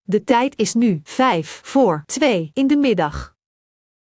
Met één druk op de knop spreekt de klok de tijd, datum en temperatuur helder uit.
• Spreekt de tijd, temperatuur en datum in het nederlands.
Spraakfragment: